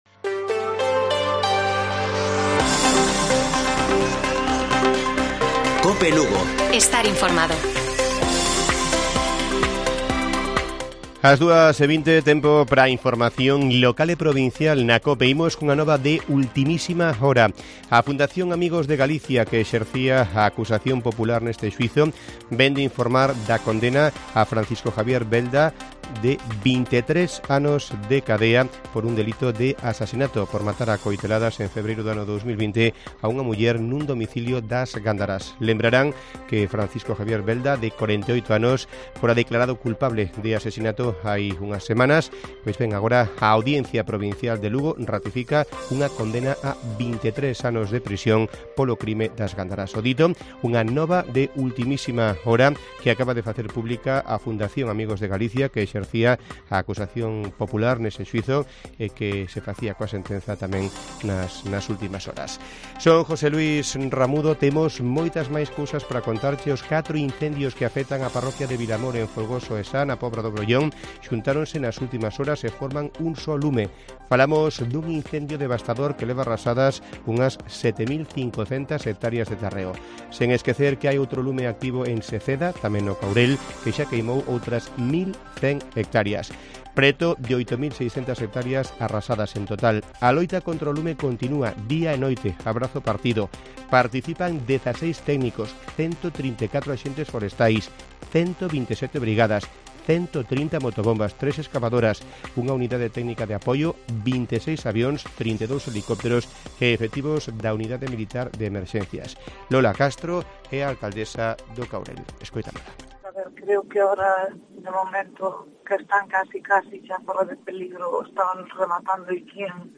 Informativo Mediodía de Cope Lugo. 20 de julio. 14:20 horas